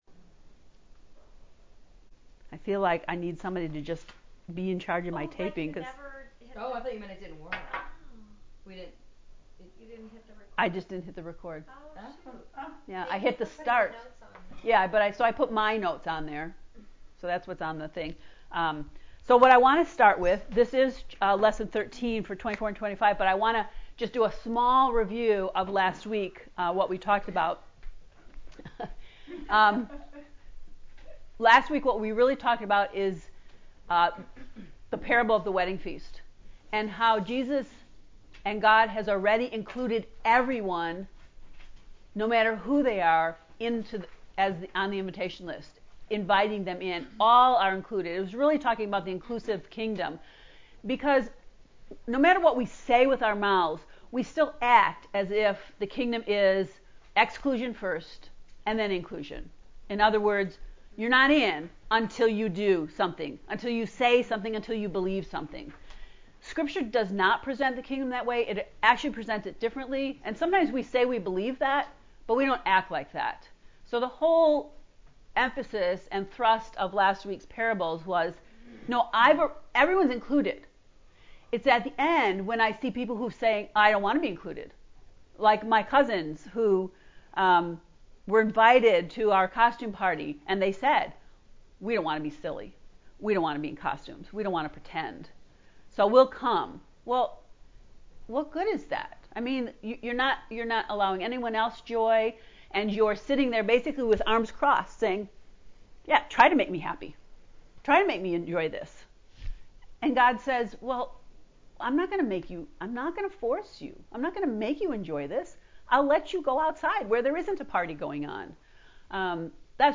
To listen to the lecture from lesson 13 “Coming, Coming…” click below: